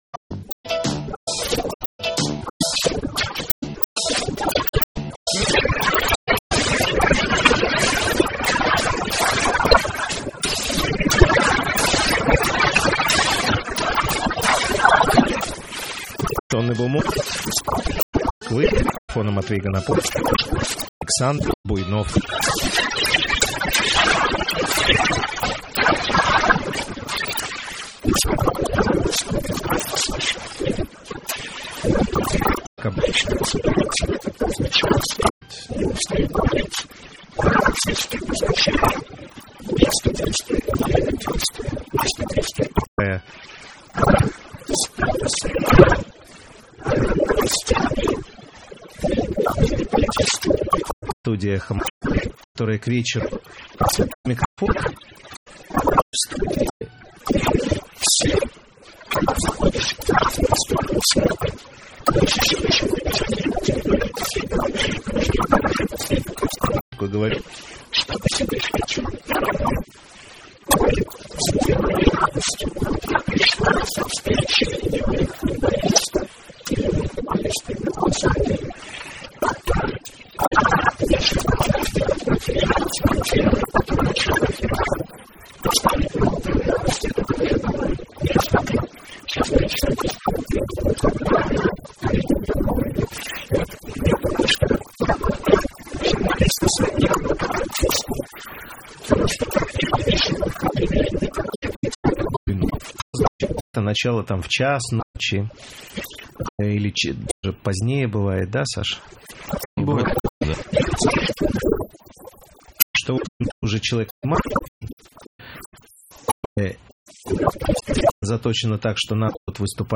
В эфире радиостанции «Эхо Москвы» - Александр Буйнов, певец.